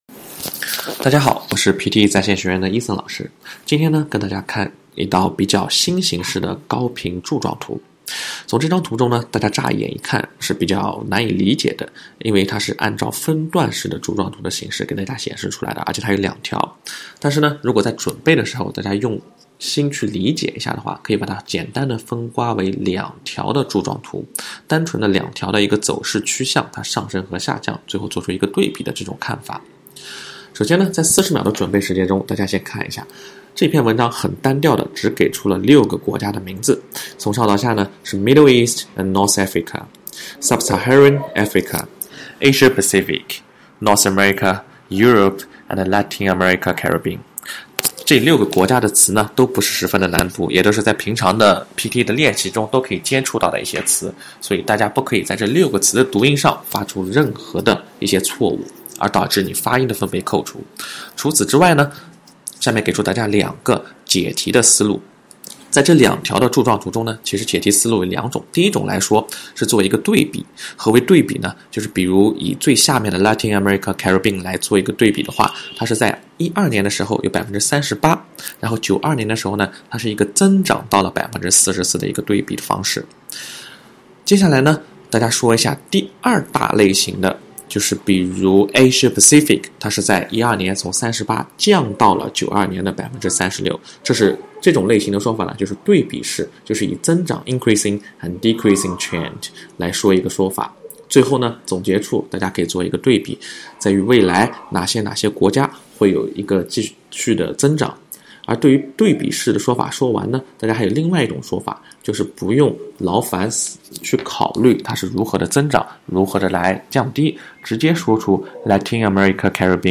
第二步，听老师讲解和示范，比较出自己描述、发音、语调、断句等有问题的地方
DI-地区（人口）分布讲解示范.mp3